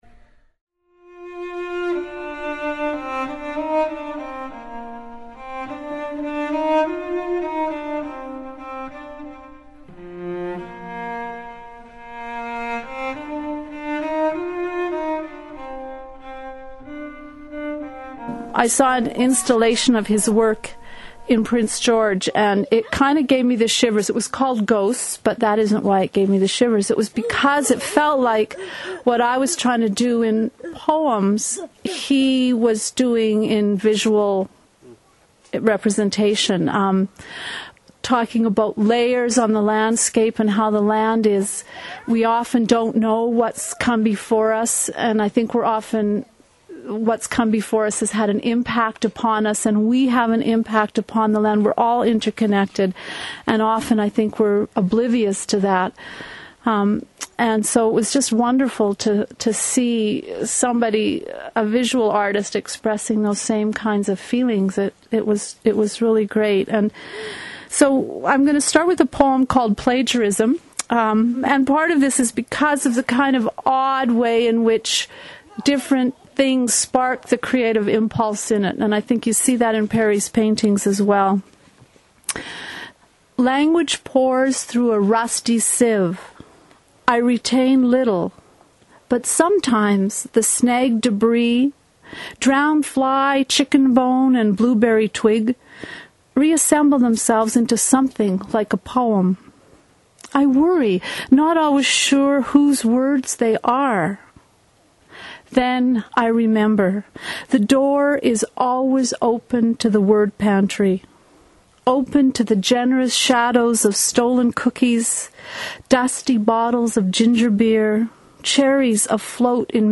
Audio clips from the 2007 book launch